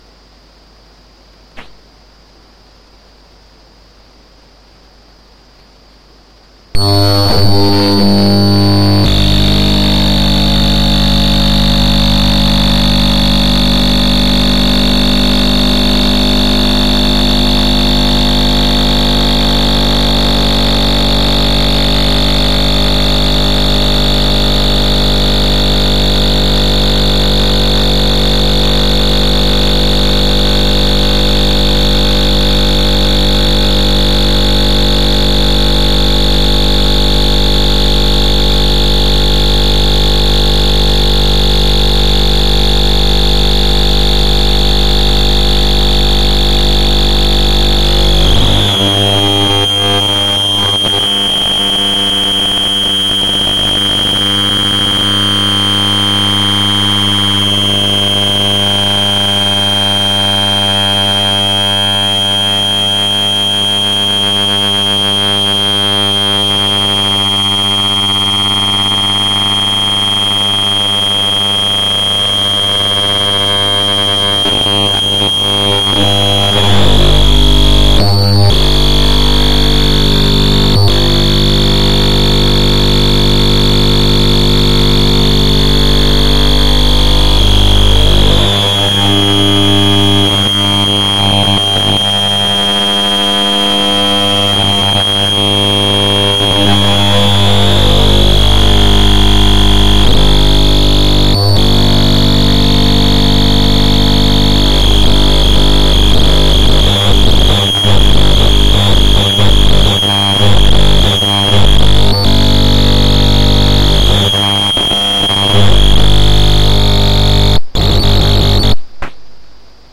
Light bulb with dimmer switch ultrasound recording using ultrasound microphone.
15 sept 2013 ultrasound dimmer energy saving bulb